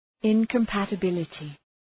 Προφορά
{,ınkɒmpətə’bılətı}